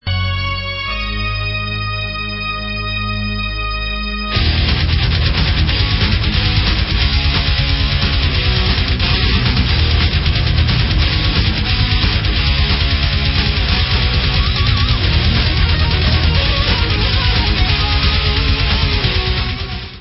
CZECH FEMALE FRONTED POWER METAL BAND